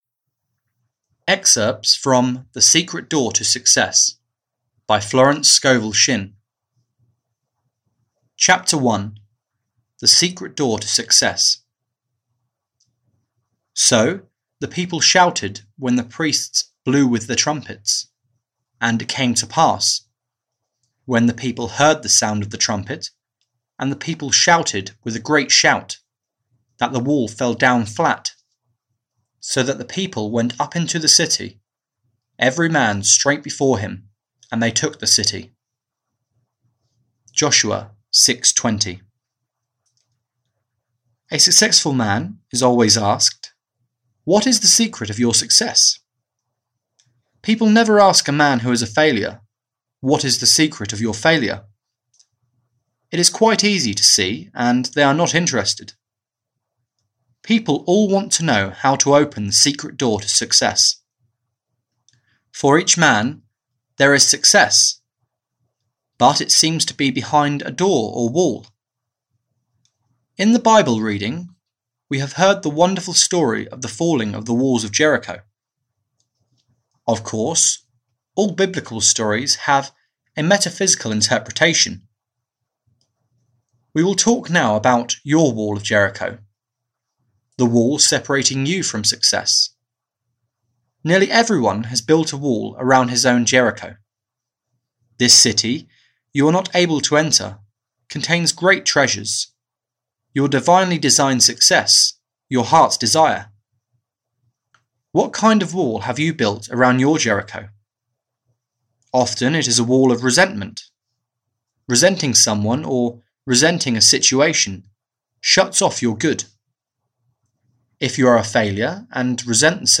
The Secret Door (EN) audiokniha
Ukázka z knihy